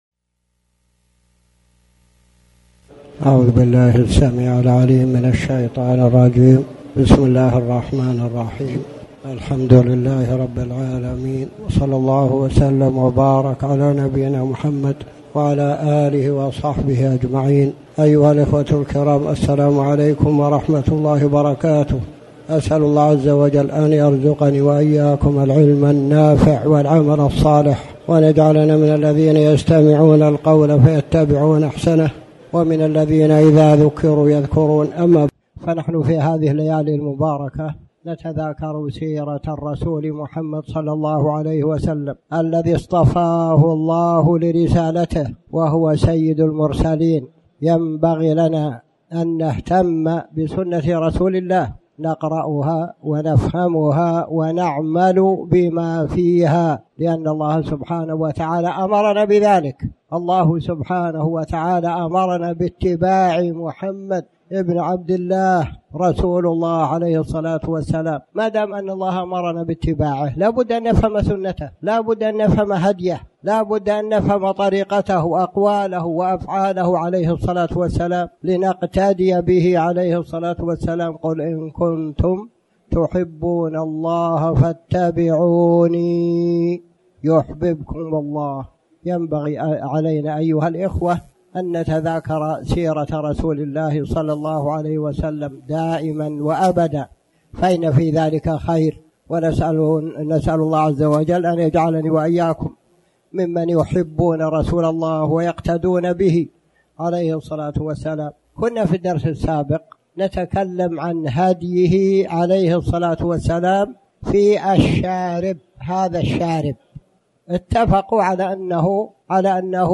تاريخ النشر ١٠ رجب ١٤٣٩ هـ المكان: المسجد الحرام الشيخ